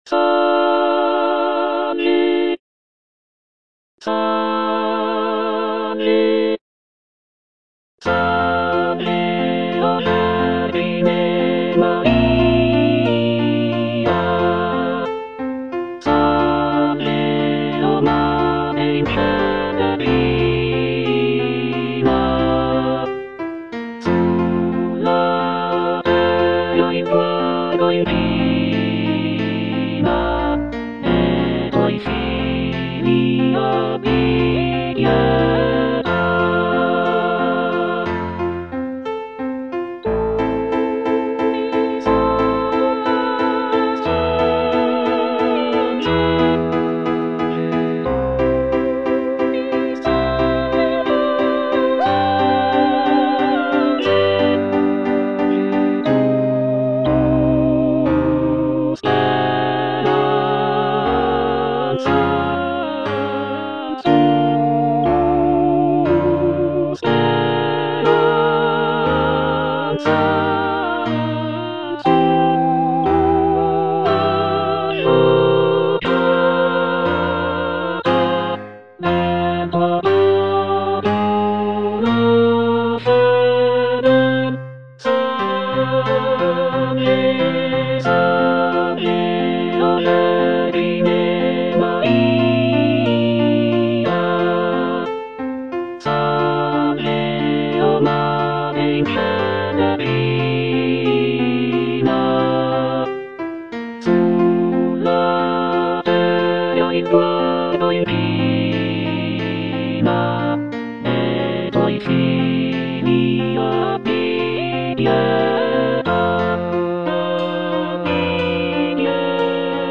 G. ROSSINI - SALVE O VERGINE MARIA (EDITION 2) (All voices) Ads stop: auto-stop